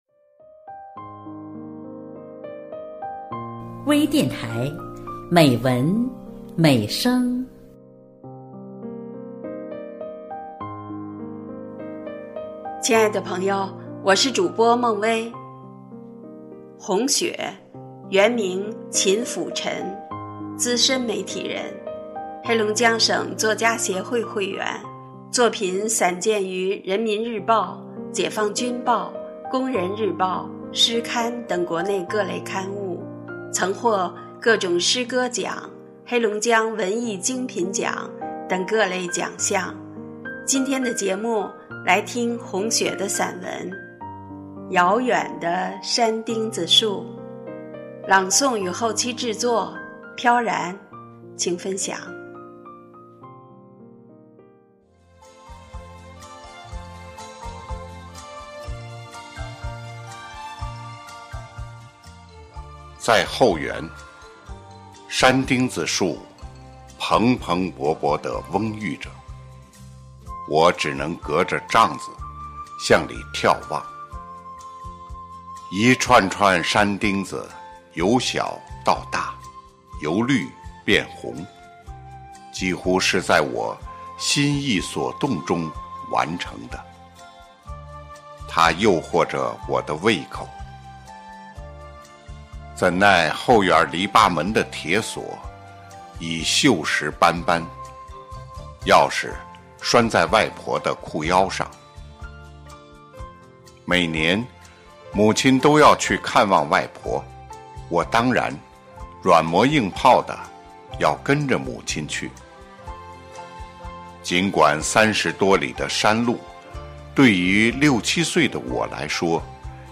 专业诵读